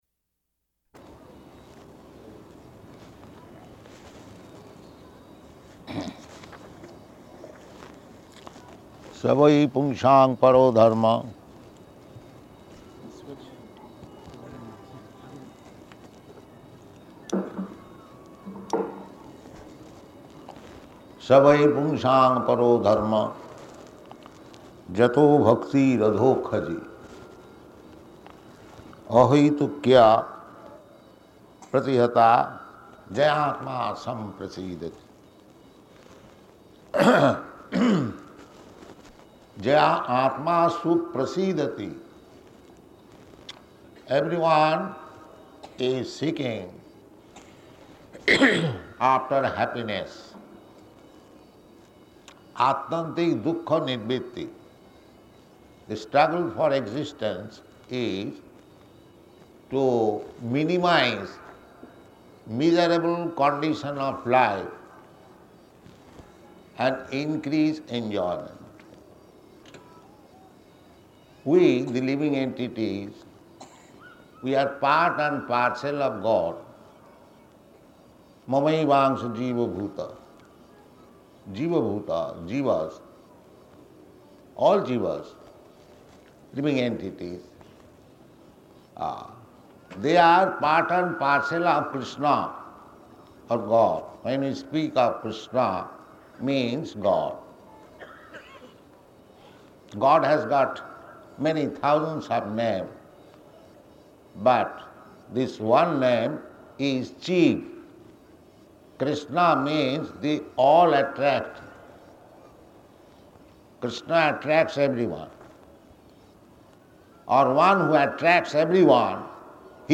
Location: Delhi